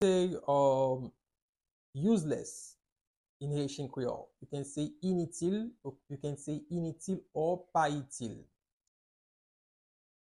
Listen to and watch “Initil” audio pronunciation in Haitian Creole by a native Haitian  in the video below:
18.How-to-say-Useless-in-Haitian-Creole-–-Initil-pronunciation.mp3